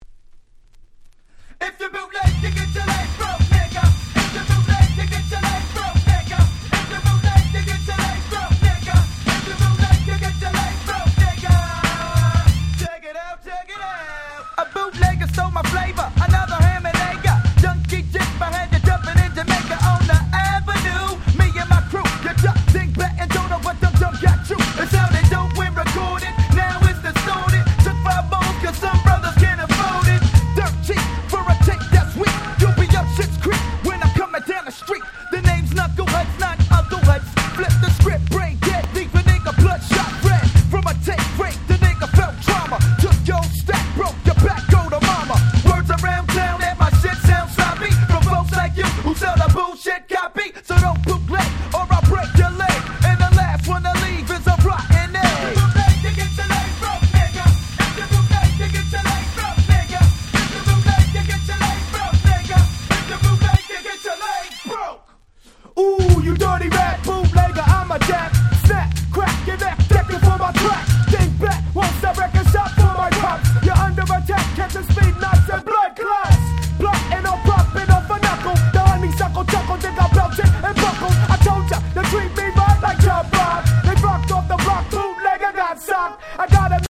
93' Nice Underground Hip Hop !!
90's ブーンバップ フッドラッツ